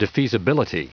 Prononciation du mot defeasibility en anglais (fichier audio)
defeasibility.wav